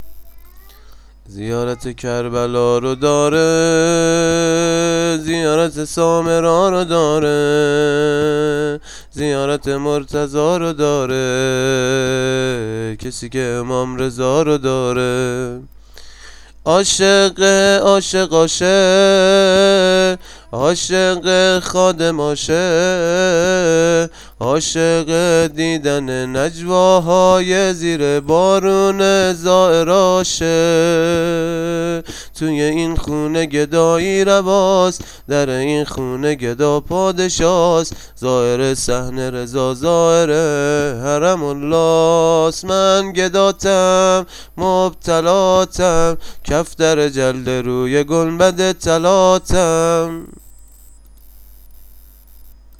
عنوان : دانلود سبک ولادت امام هشتم